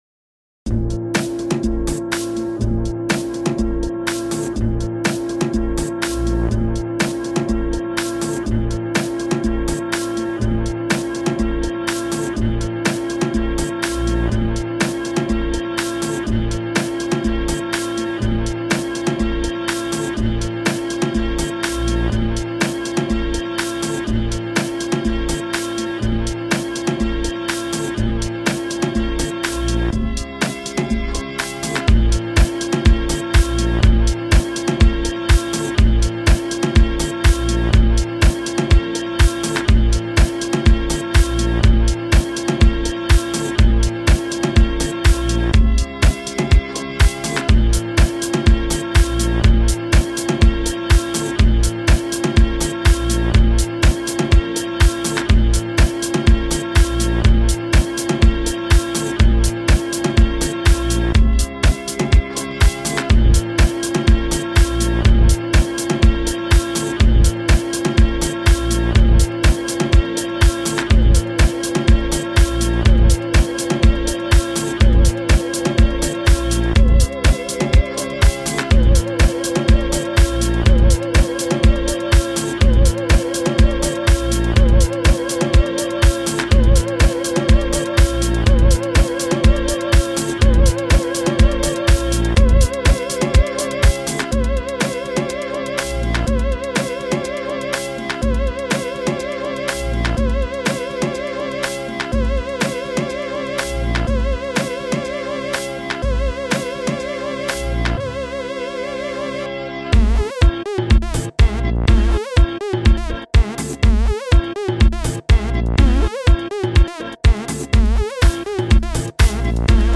sun is shining, melodies are sweet